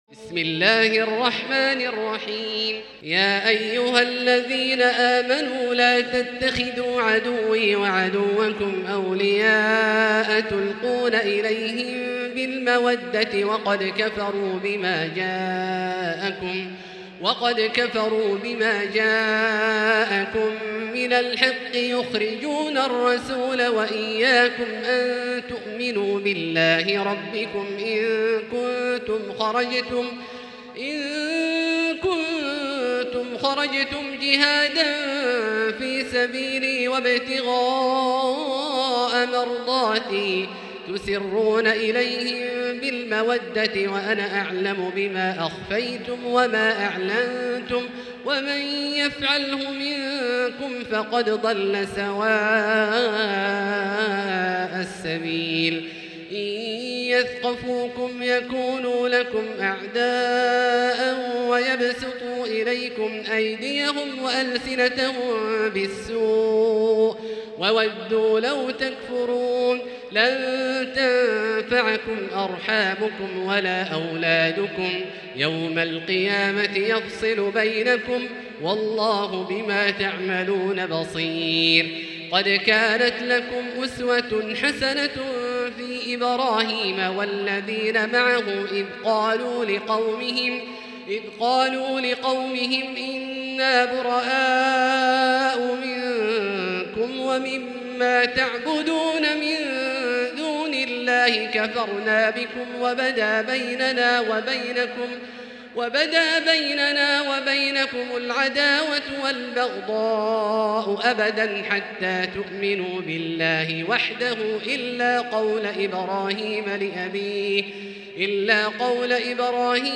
المكان: المسجد الحرام الشيخ: فضيلة الشيخ عبدالله الجهني فضيلة الشيخ عبدالله الجهني الممتحنة